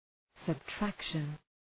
Προφορά
{səb’trækʃən}
subtraction.mp3